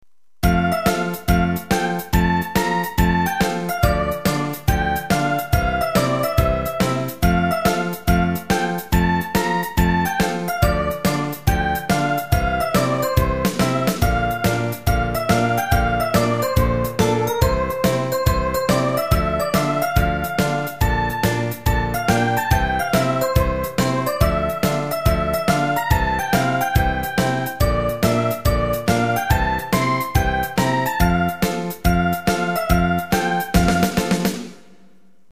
BGM：日常・平穏
コメント 日常的な感じ。